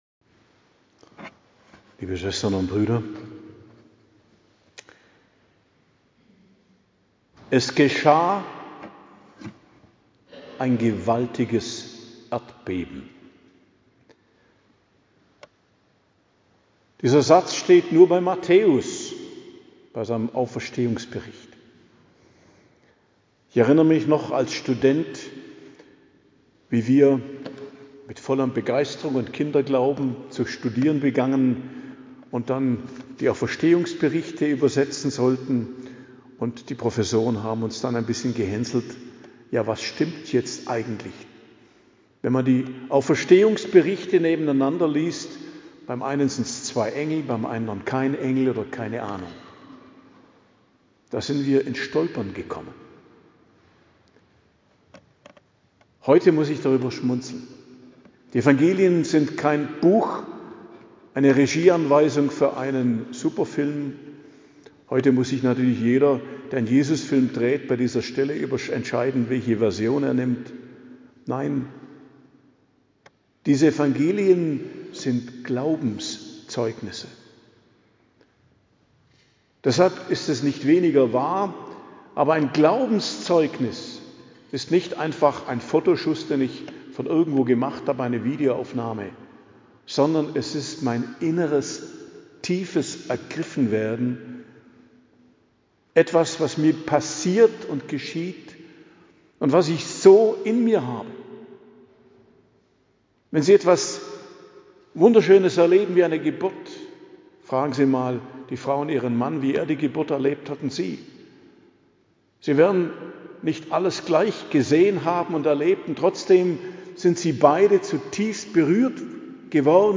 Predigt zur Feier der Osternacht, 4.04.2026 ~ Geistliches Zentrum Kloster Heiligkreuztal Podcast